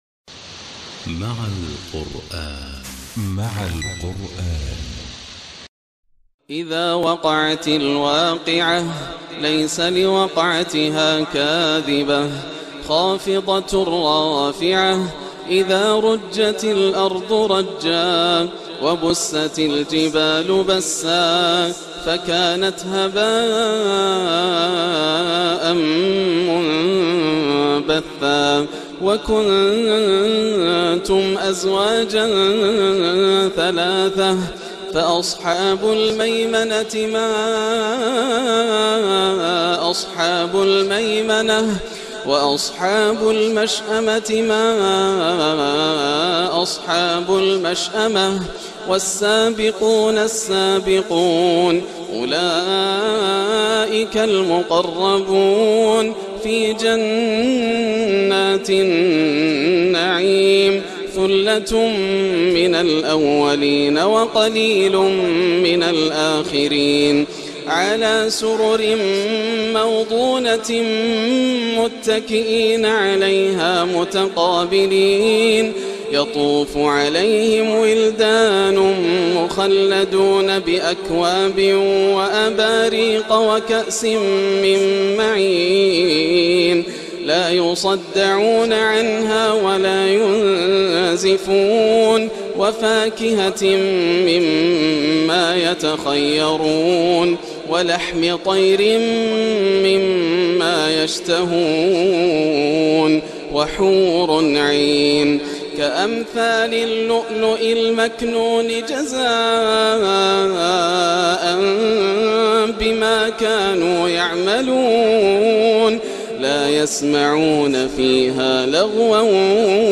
عشاء الاربعاء 8-7-1438هـ سورة الواقعة جامع الملك فهد بجدة > عام 1438 > الفروض - تلاوات ياسر الدوسري